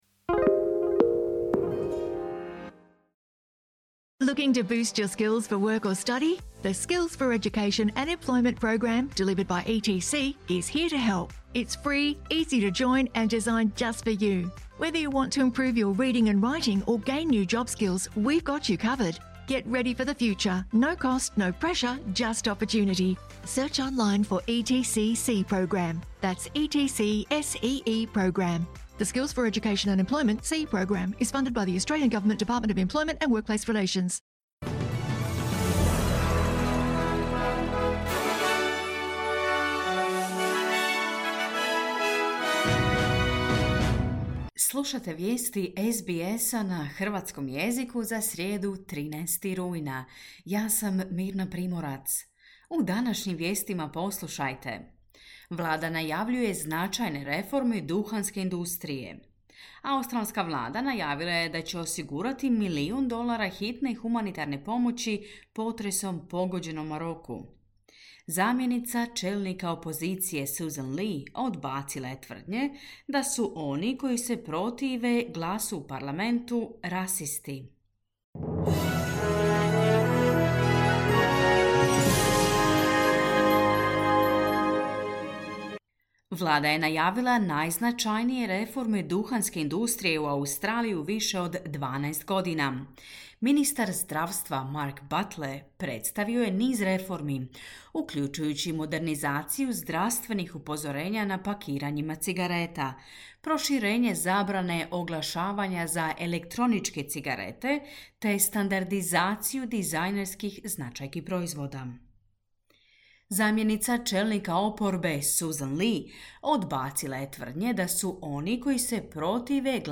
Vijesti, 13.9.2023.
Vijesti radija SBS na hrvatskom jeziku.